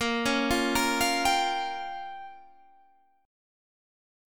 A#m6 chord